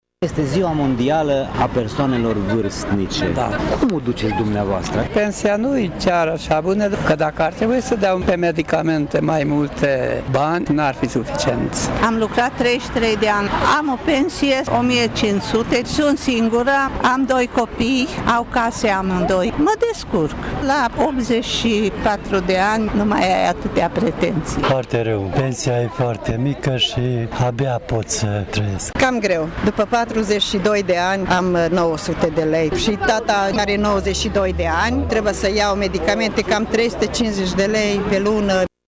Mâine, 1 octombrie, este Ziua Mondială a Persoanelor Vârstnice, prilej cu care am încercat să stăm de vorbă cu câțiva dintre „seniorii” Tîrgu-Mureșului.